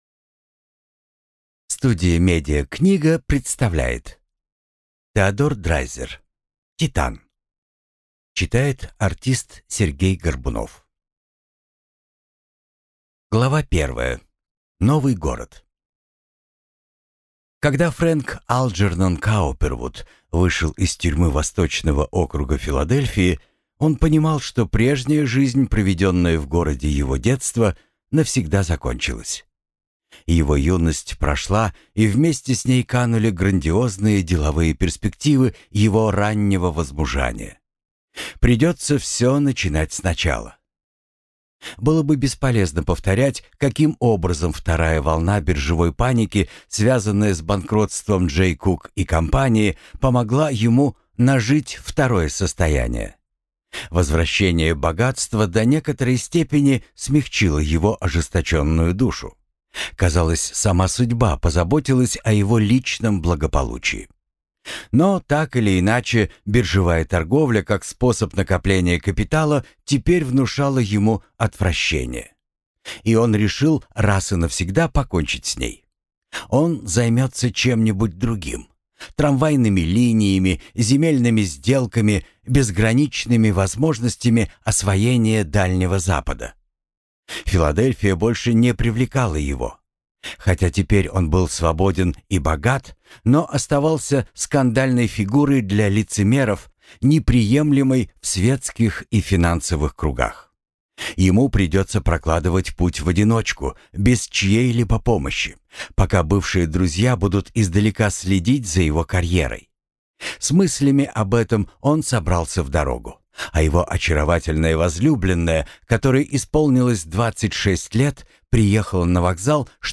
Аудиокнига Титан | Библиотека аудиокниг
Прослушать и бесплатно скачать фрагмент аудиокниги